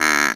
ANIMAL_Duck_01_mono.wav